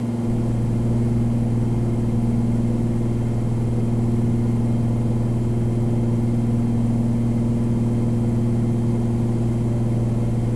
propeller-aircraft-engine-noise.mp3